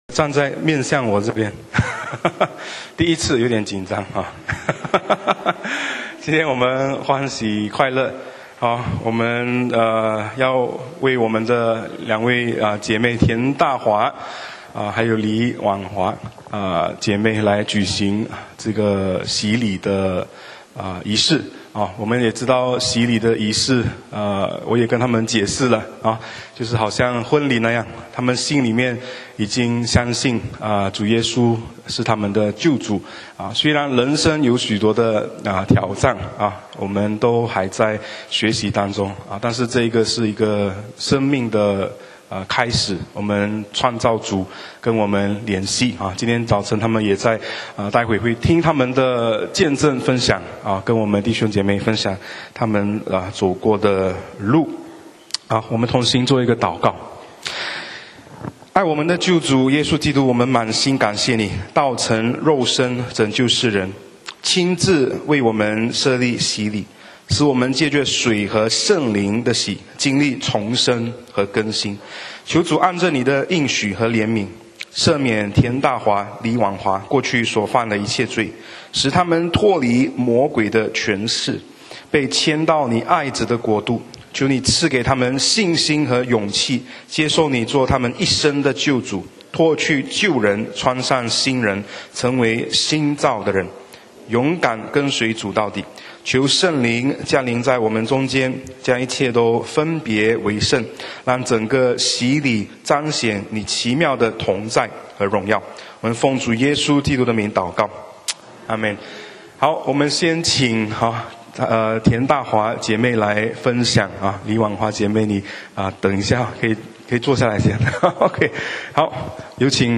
講道 題目：旷野中的迷失 經文： 伯1:1-3, 6-12, 20-22, 2;3-10 1乌斯地有一个人名叫约伯。